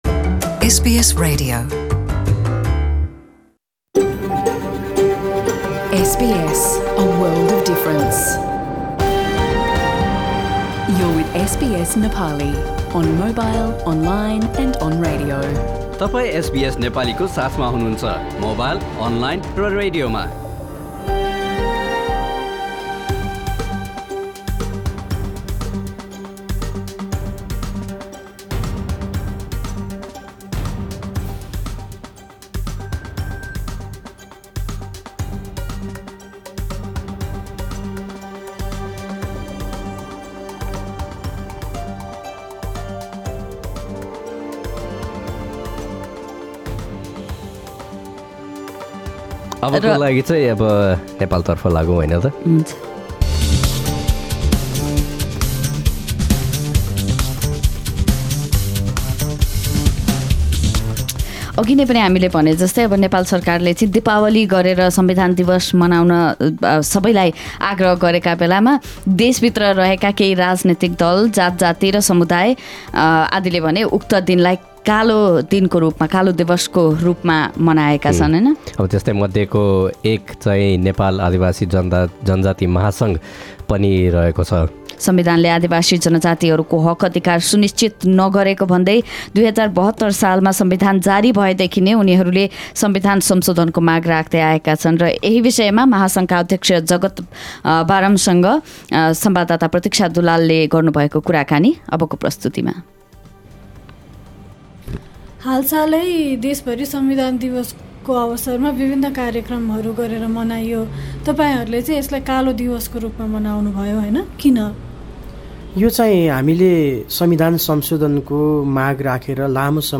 एसबीएस नेपाली पोडकास्ट